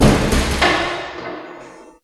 hit hospital percussion sound effect free sound royalty free Memes